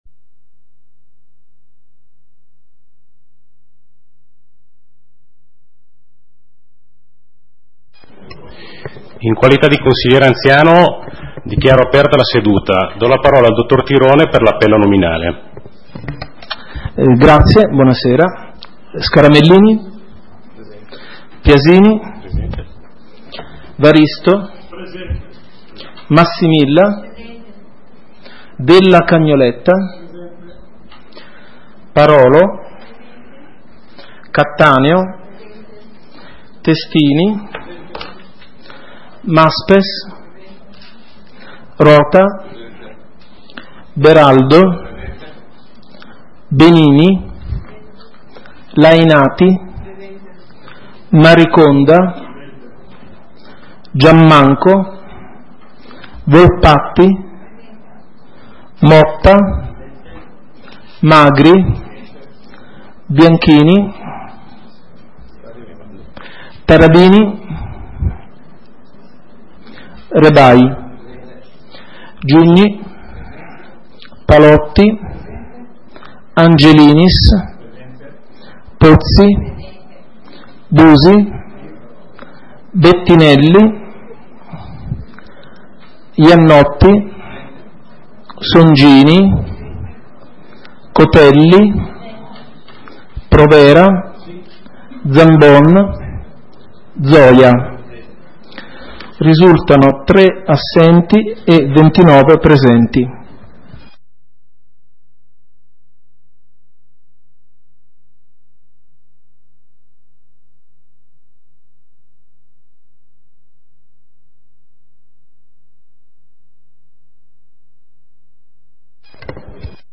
Seduta consiglio comunale del 09 luglio 2018 - Comune di Sondrio
Ordine del giorno ed audio della seduta consiliare del Comune di Sondrio effettuata nella data sotto indicata.